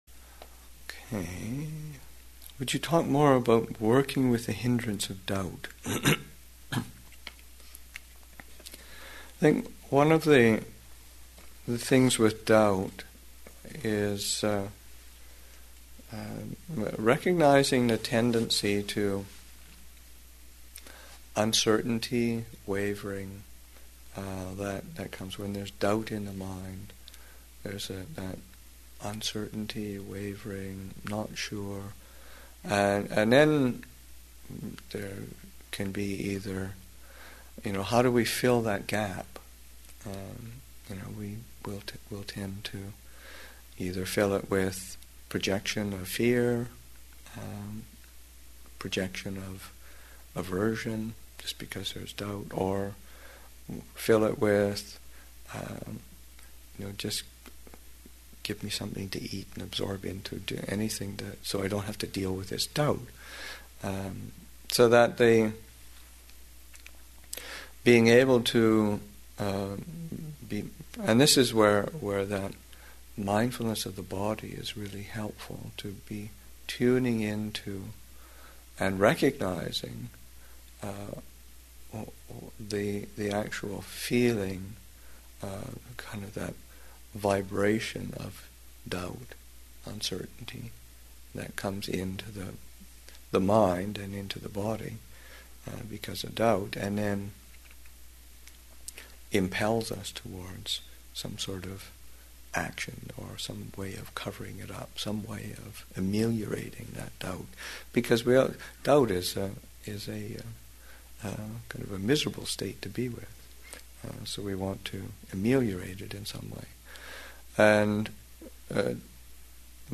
Metta Retreat, Session 2 – Sep. 10, 2008